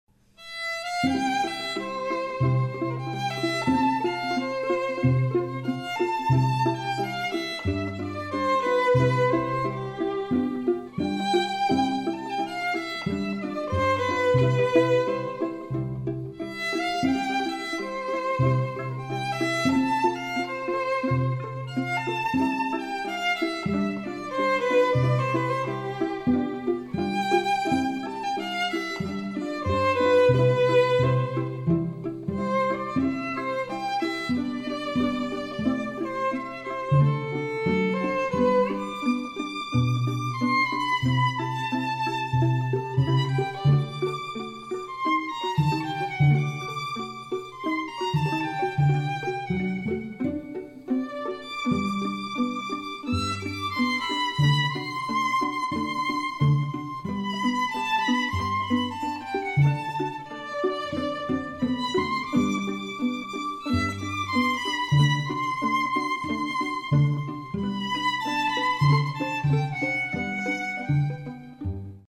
Репертуар дворца | Струнный квартет Нона